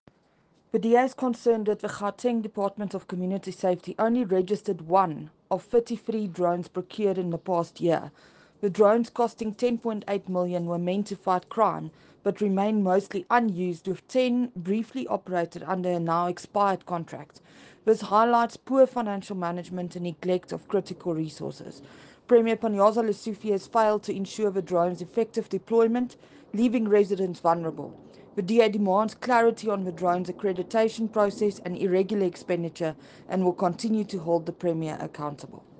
Afrikaans soundbites attached by Crezane Bosch MPL.